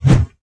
naga_commander_swish.wav